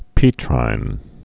(pētrīn)